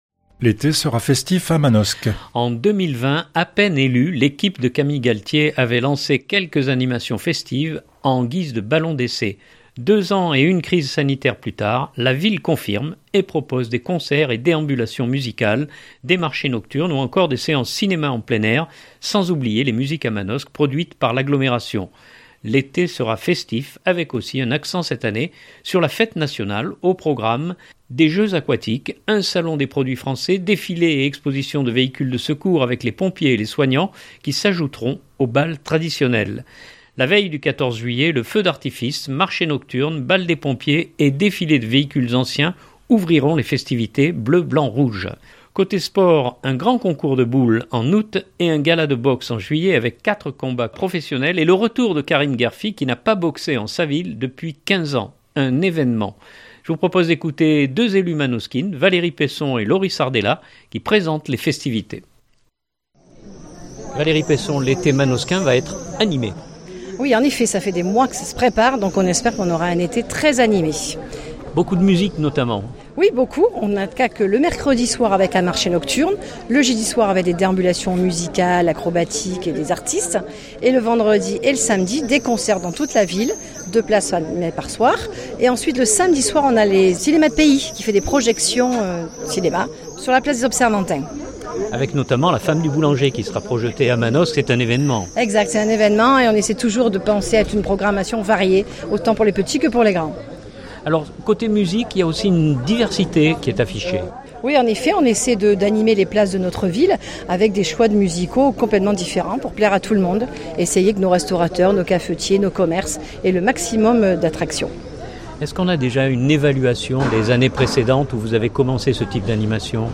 Je vous propose d’écouter deux élues manosquines Valérie Peisson et Laurie Sardella qui présentent les festivités.